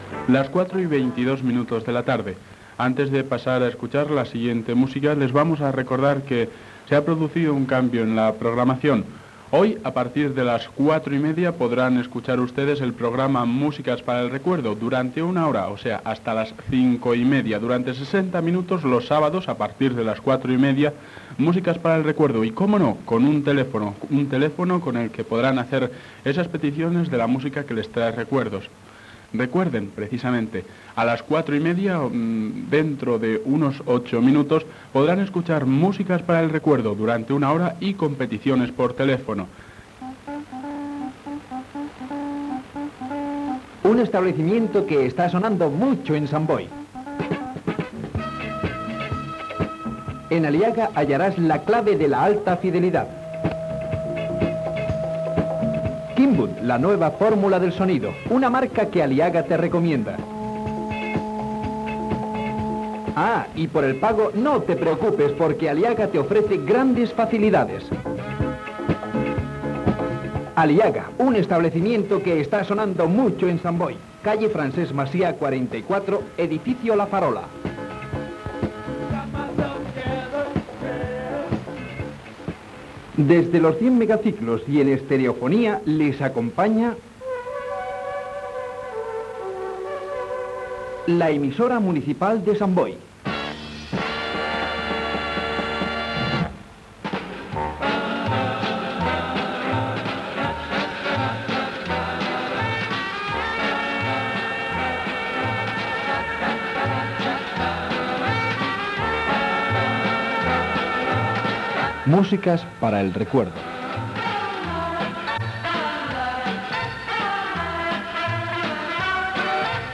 Canvi a la programació, publicitat, indicatiu, inici del programa i trucada telefònica demanant un mambo.
Musical